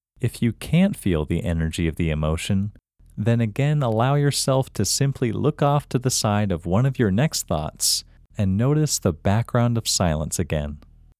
Locate IN English Male 31